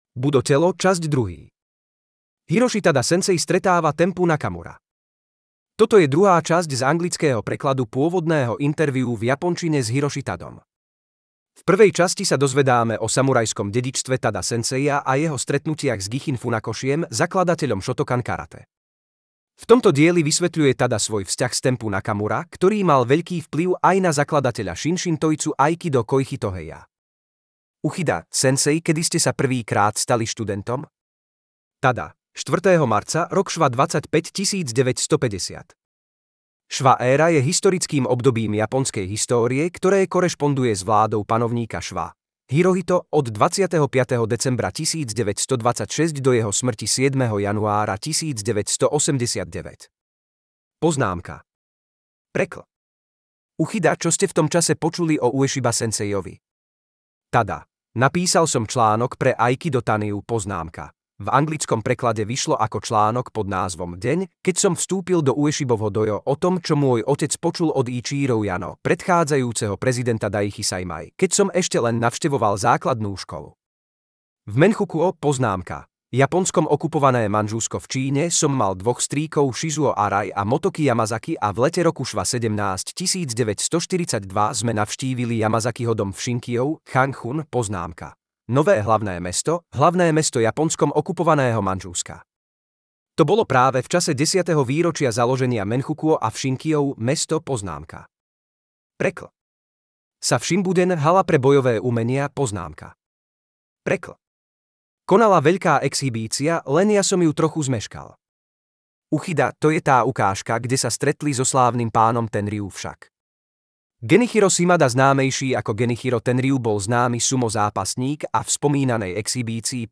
rozhovor - Tada Hiroshi - Budo telo - časť 2 - Aikido Dojo Trnava